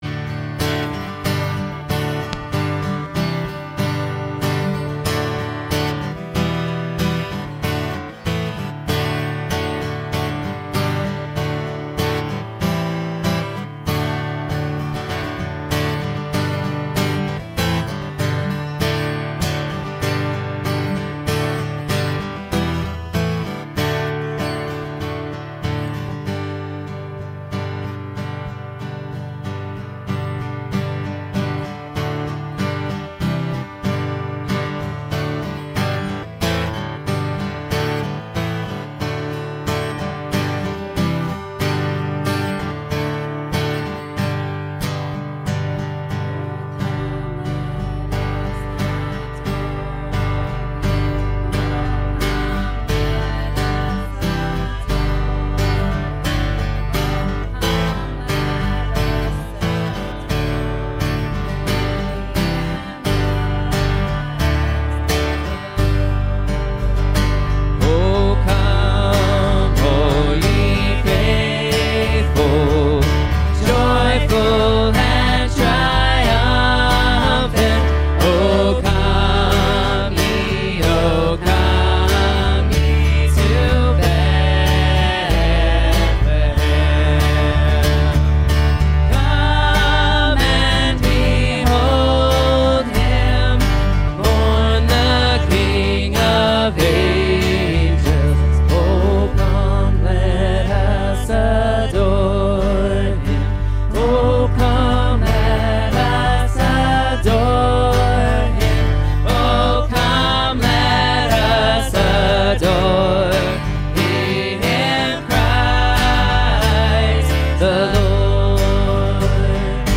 2023-12-17 Jesus Gives Us Something to Look Forward To Passage: John 16:16-33 Service Type: Sunday Morning Youversion Event Following Jesus can be CONFUSING.